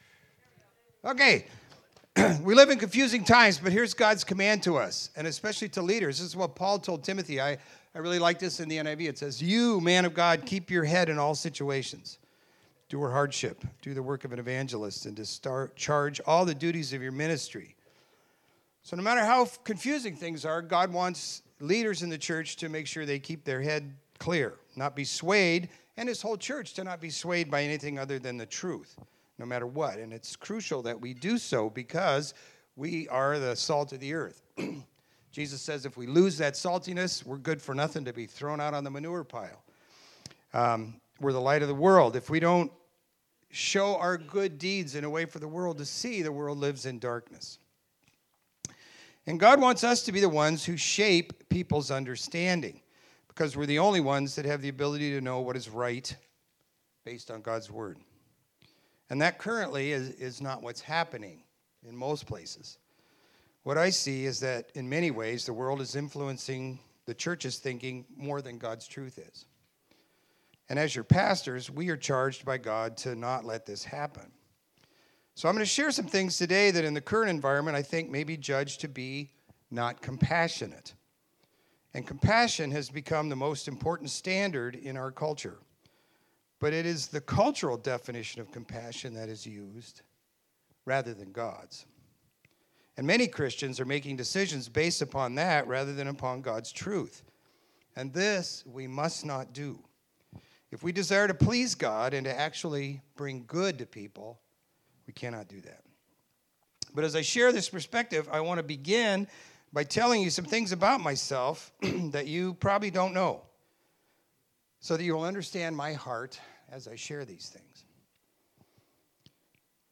Video Audio Download Audio Home Resources Sermons Getting a Grip on Current Events Jan 25 Getting a Grip on Current Events This Sunday the pastors will briefly share a Biblical view on the events surrounding the controversy raging in our state over deportation, and then we will spend time praying for God’s will to be done.